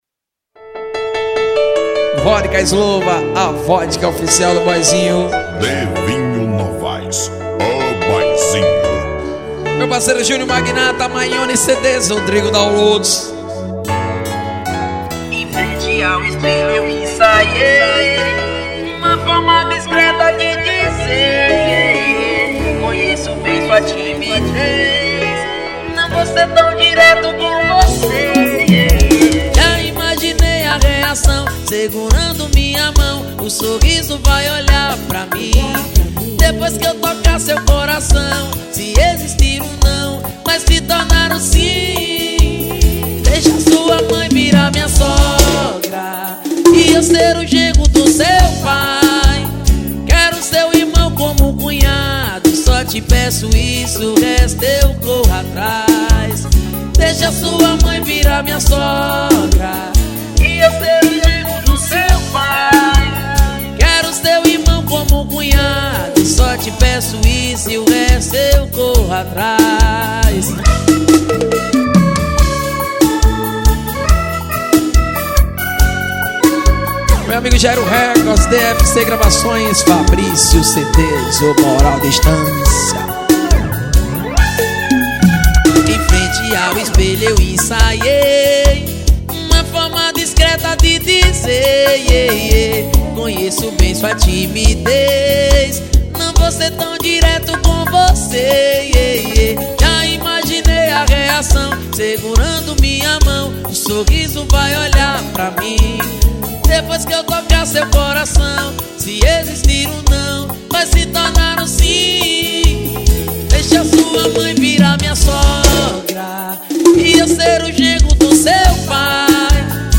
2024-07-07 23:58:51 Gênero: Sertanejo Views